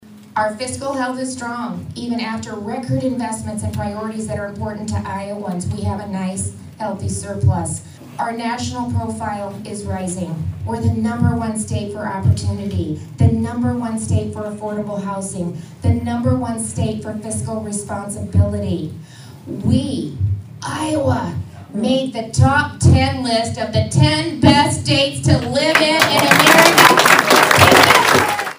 Reynolds told the crowd of around 125 people at the Carroll County GOP Headquarters that Republicans’ commitment to fiscal responsibility, lowering taxes, and support for law and order has made Iowa one of the best places in the nation to live.
Carroll-Rally-1.mp3